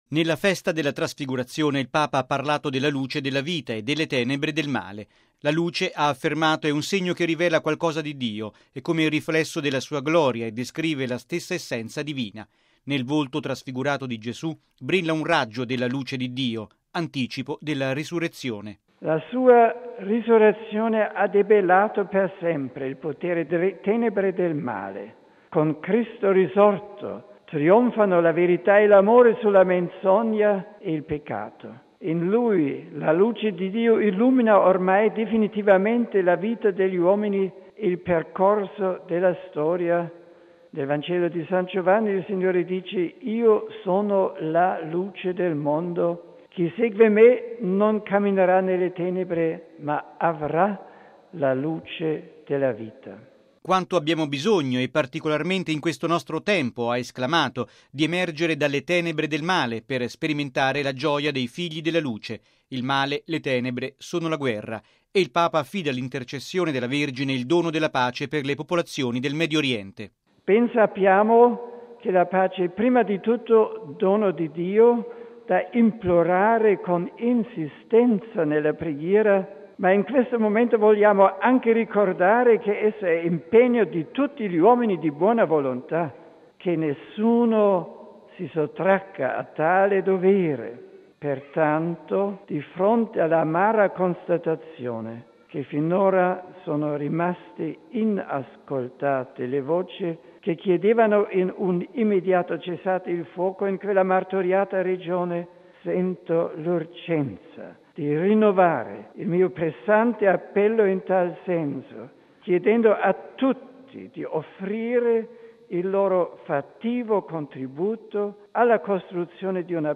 (6 agosto 2006 - RV) Il Papa oggi all’Angelus a Castel Gandolfo ha lanciato un nuovo appello di pace “per le popolazioni del Medio Oriente sconvolte da lotte fratricide”.
Il servizio